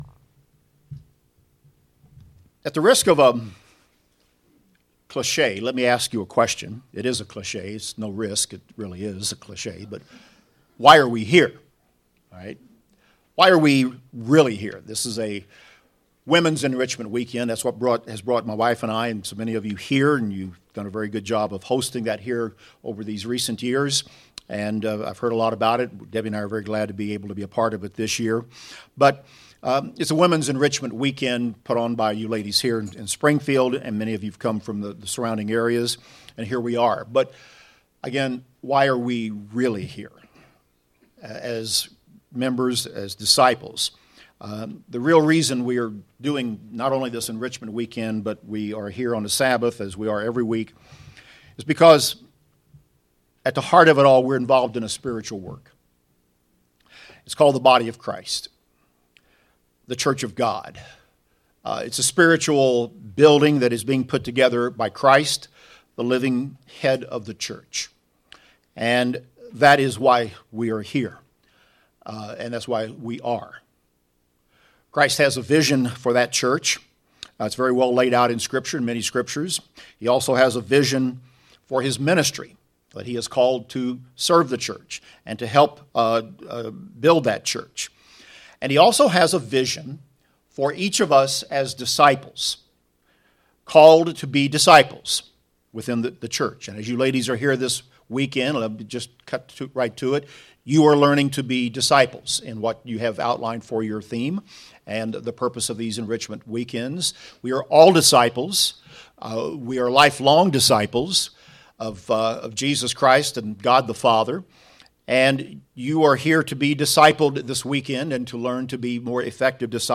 Building off an earlier sermon, this message looks into the theme of Proverbs 31 as it relates to the Church of God. It delves into the duty of a church and its role as a family in relation to how we treat and care for one another.